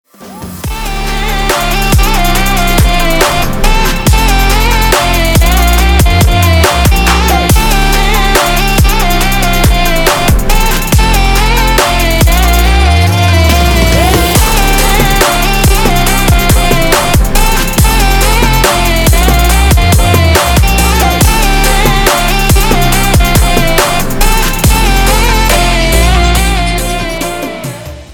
• Качество: 320, Stereo
громкие
dance
Electronic
EDM